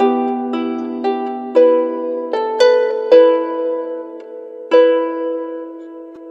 Harp04_114_G.wav